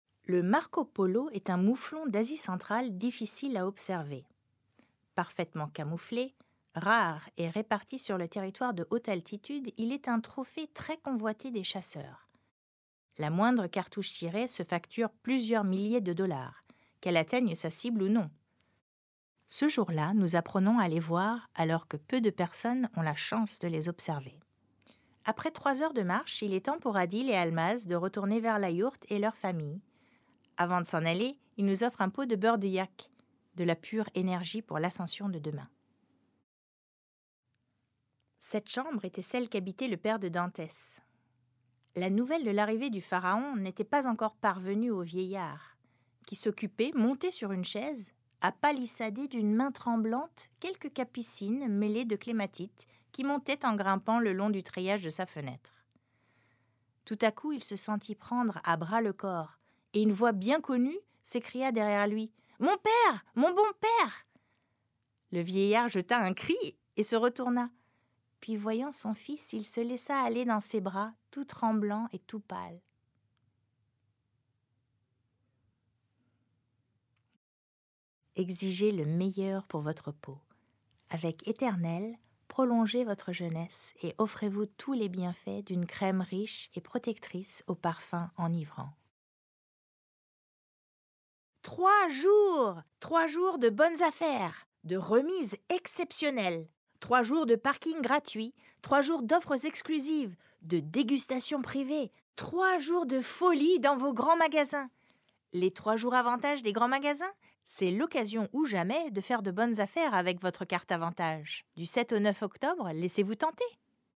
Bande démo
Voix off